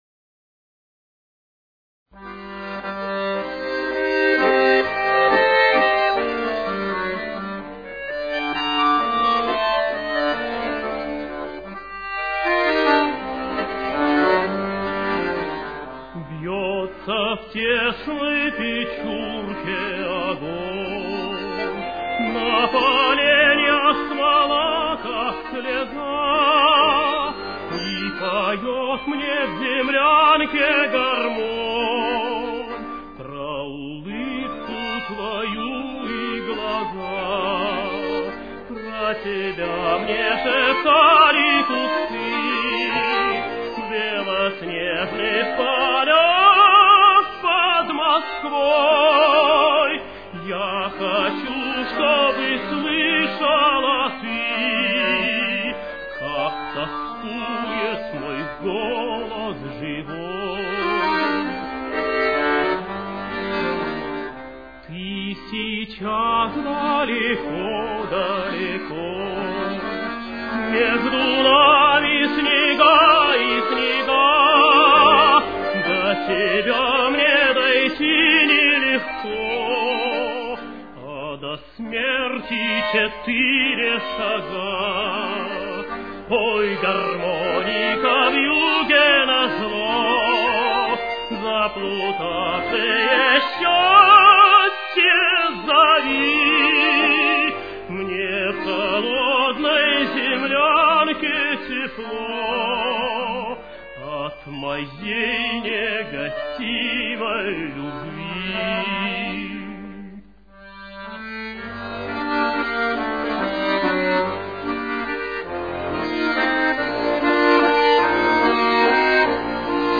с очень низким качеством (16 – 32 кБит/с)
Темп: 136.